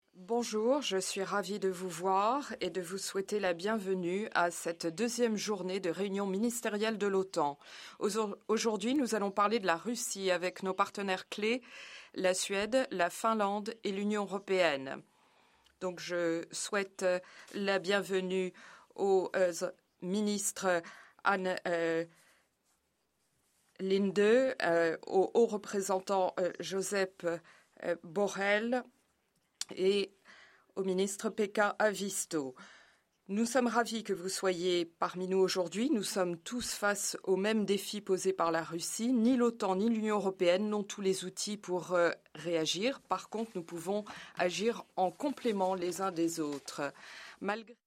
ORIGINAL - Opening remarks by NATO Secretary General Jens Stoltenberg at the meeting of the North Atlantic Council in Foreign Ministers’ session